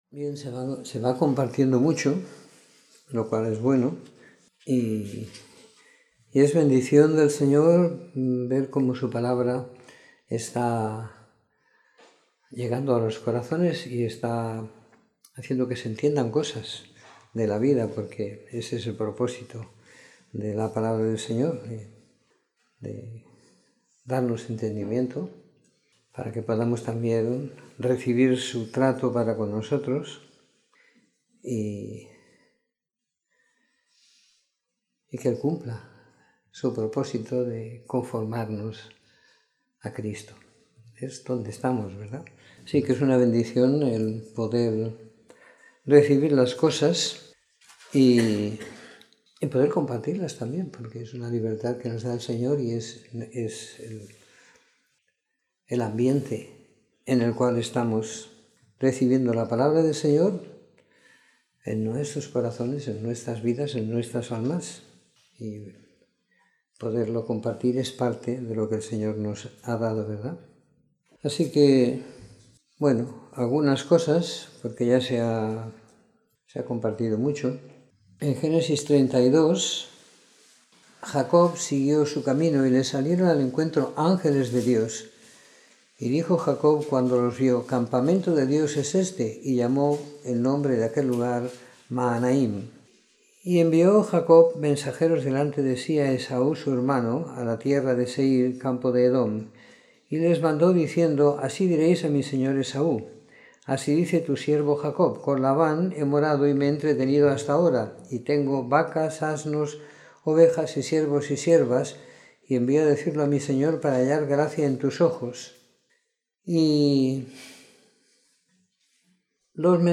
Comentario en Génesis 29-50 - 19 de Enero de 2018
Escuchar la Reunión / Descargar Reunión en audio Comentario en el libro de Génesis del capítulo 29 al 50 siguiendo la lectura programada para cada semana del año que tenemos en la congregación en Sant Pere de Ribes.